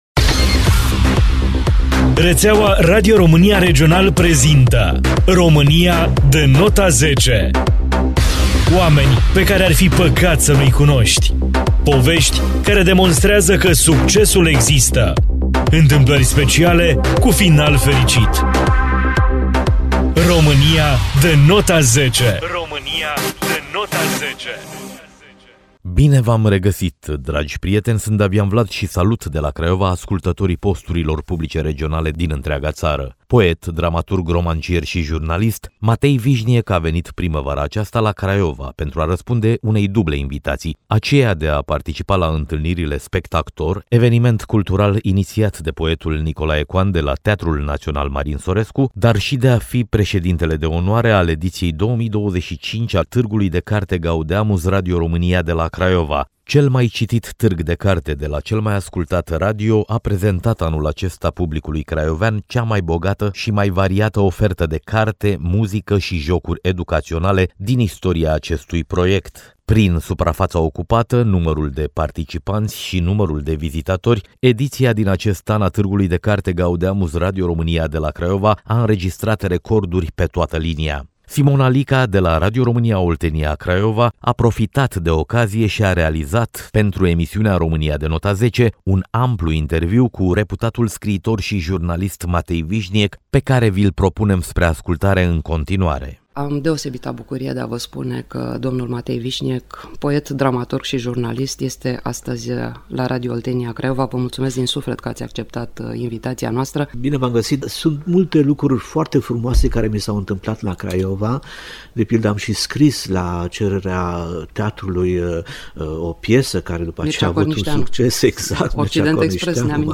un amplu interviu cu reputatul scriitor și jurnalist Matei Vișniec.